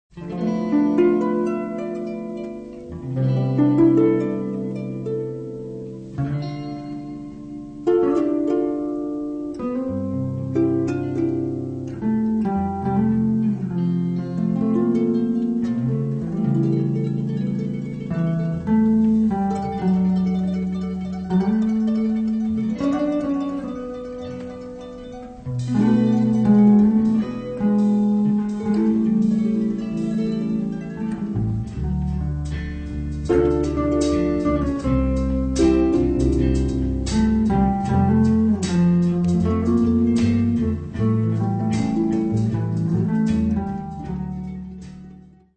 Zwei Soundtracks zu deutschen Aufklärungsfilmen (1968/69)